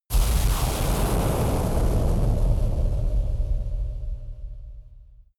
Royalty free sounds: Explosions